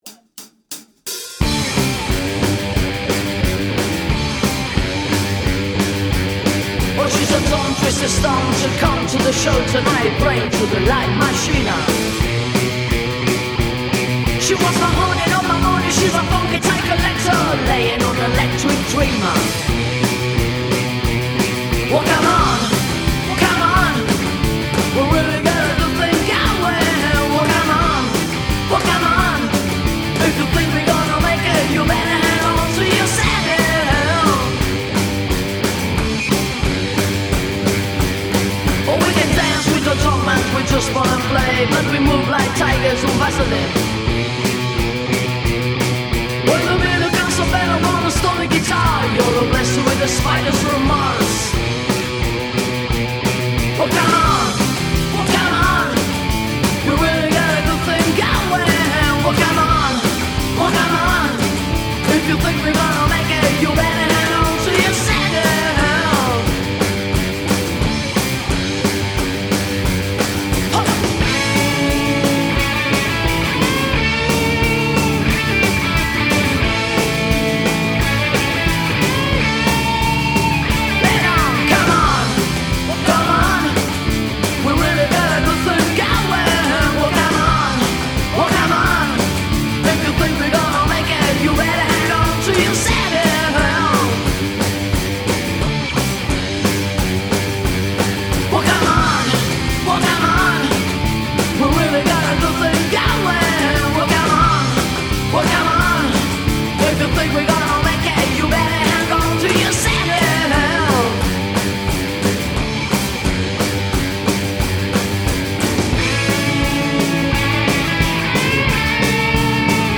batteria, percussioni
armonica, flauto, sassofono, tastiere